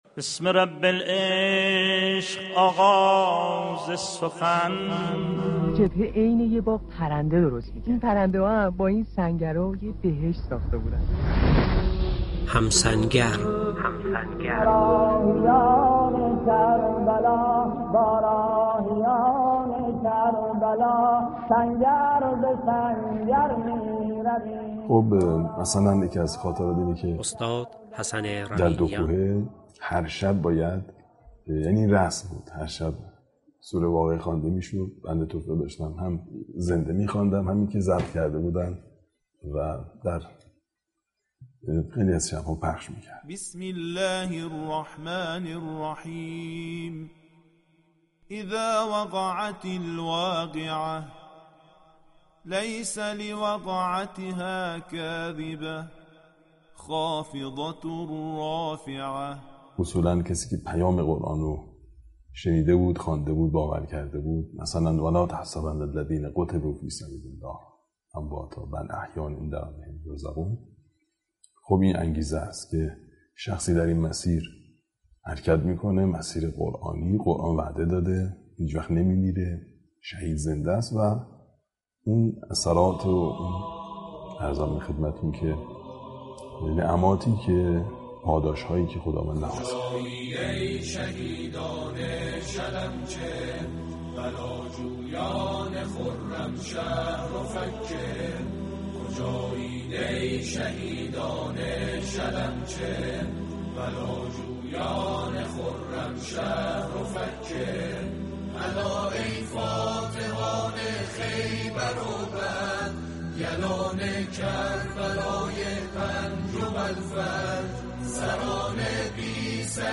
او فضای برنامه را با شعرهایی در ستایش شهیدان شلمچه، بلاجویان خرمشهر و فكه، فاتحان خیبر و بدر، و یلان عملیات كربلای پنج و فتح‌المبین آمیخت؛ تصاویری كه با واژه‌هایی چون «غریق شط خون با نام زهرا» جان تازه‌ای به یاد و خاطره مقاومت بخشید.